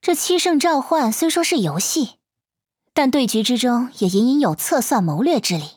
【模型】GPT-SoVITS模型编号099_女-secs